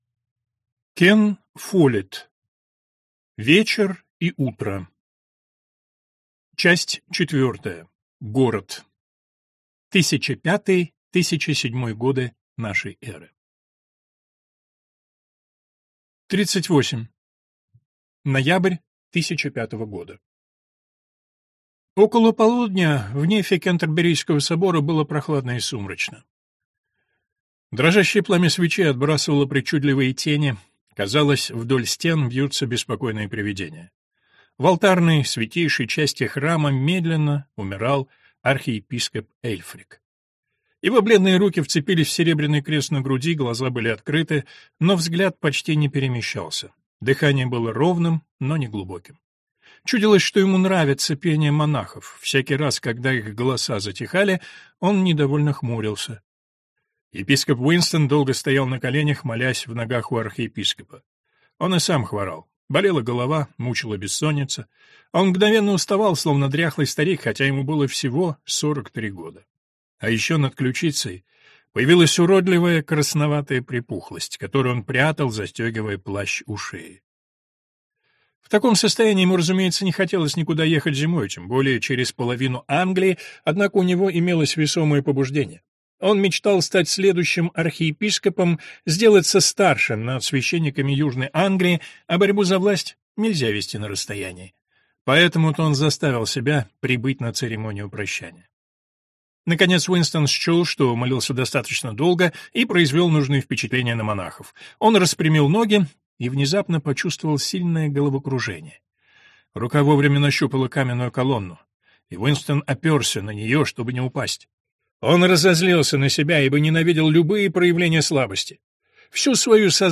Аудиокнига Вечер и утро (Часть 3) | Библиотека аудиокниг